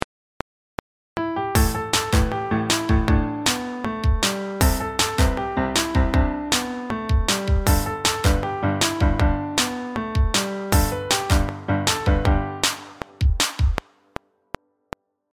作ったメロディに重ねて、別のメロディも作ってみましょう♪
最初に作ったメロディより低い声で歌ってみましょう